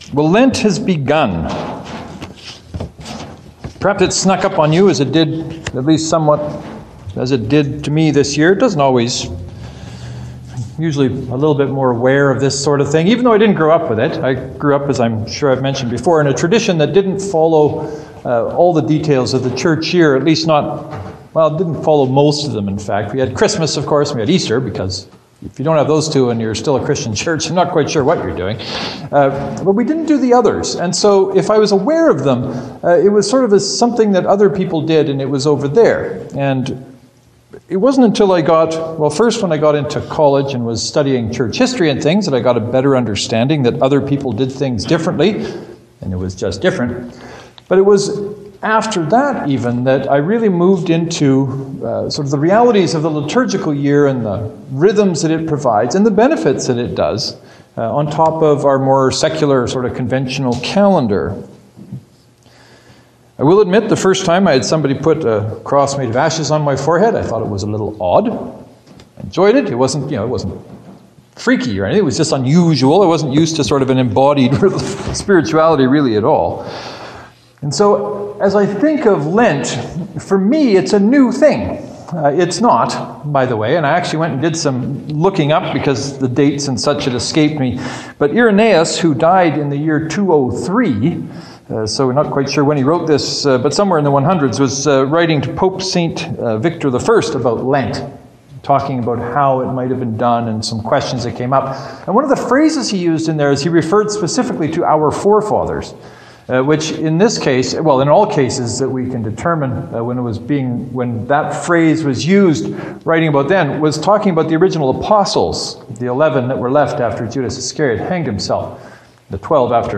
This year’s first Sunday in Lent sermons have a little history about the origins of Lent but what I was aiming at was helping us see what Lent can offer us. If we look at Lent as an opportunity to reflect on who we are, what we believe and why we believe what we believe this can be a wonderfully rich time.